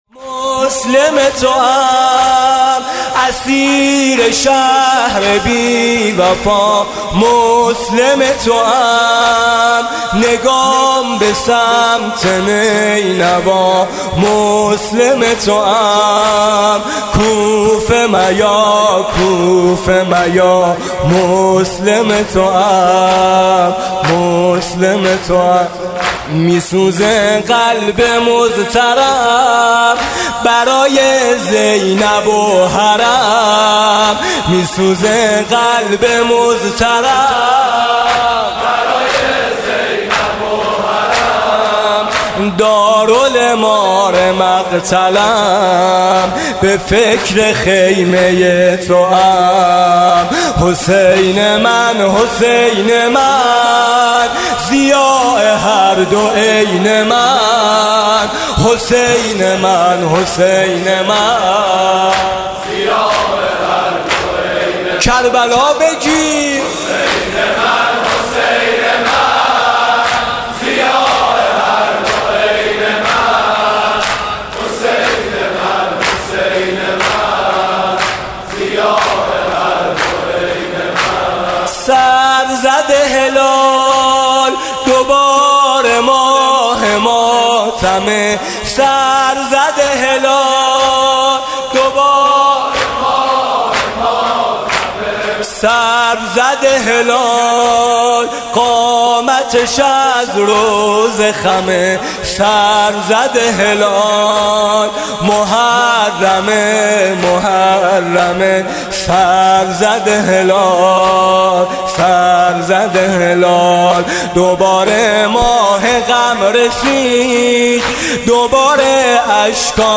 صوت مراسم شب اول محرم 1438 هیئت میثاق با شهدا ذیلاً می‌آید:
زمینه: بر تو سلام ای ماه غم! محرم! | برادر میثم مطیعی